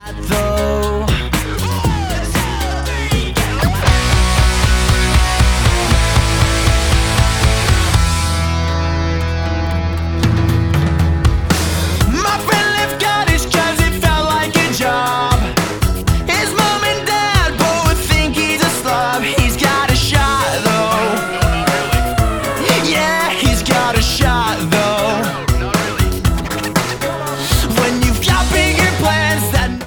• Pop Rock, power pop